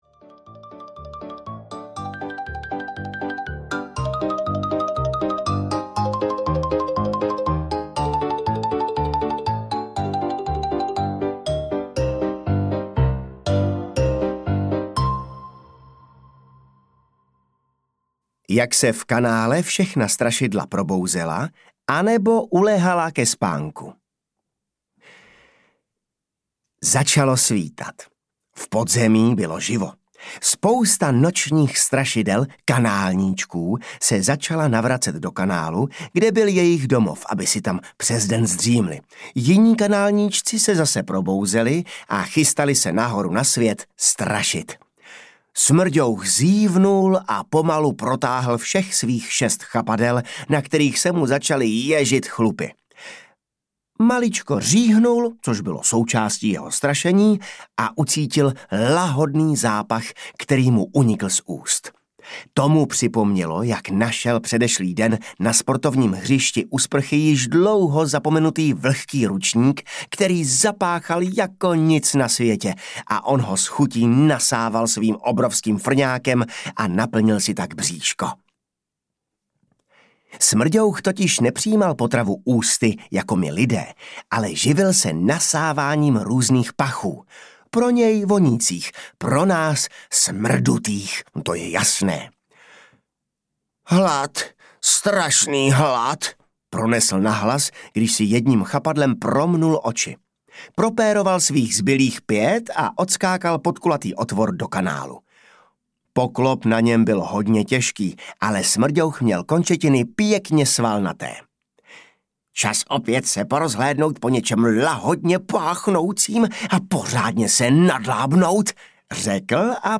Kanálníčci zachraňují svět audiokniha
Ukázka z knihy
• InterpretJan Maxián